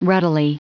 Prononciation du mot ruddily en anglais (fichier audio)
Prononciation du mot : ruddily